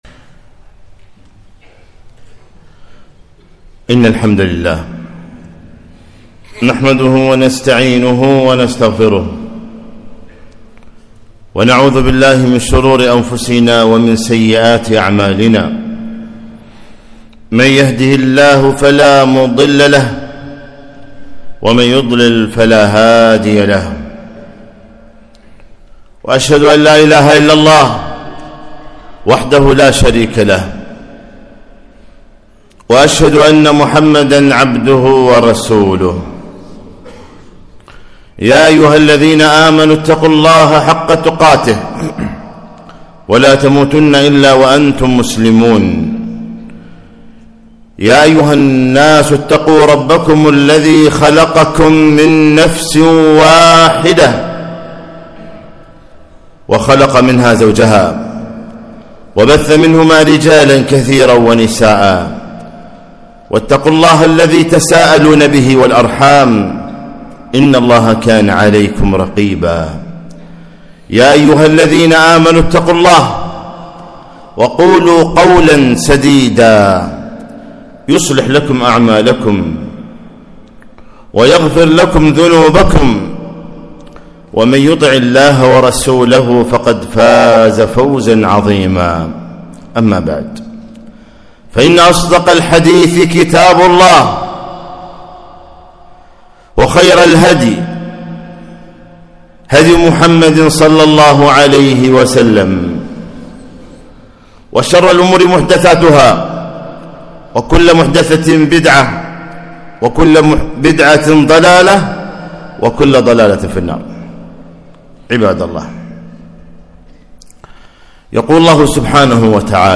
خطبة - ( ادفع بالتي هي أحسن )